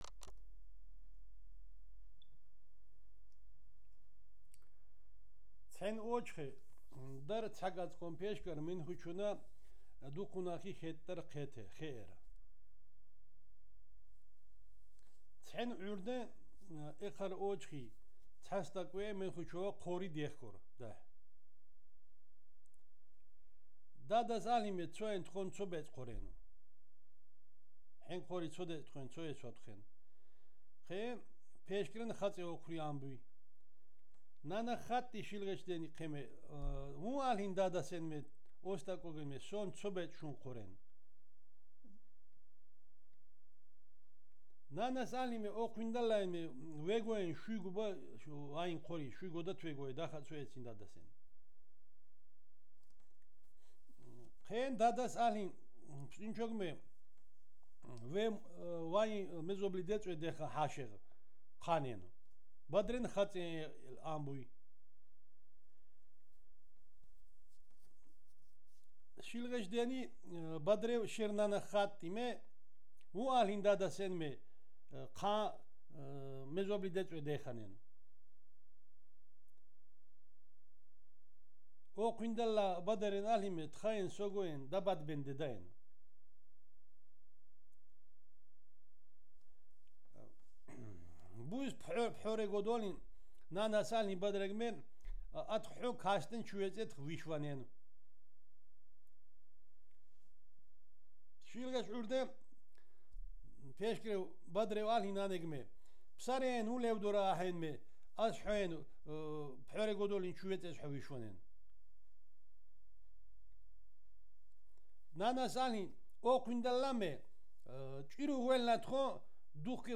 digital wav file recorded at 48 kHz/24 bit on Marantz PMD561 solid state recorder
Zemo Alvani, Kakheti, Georgia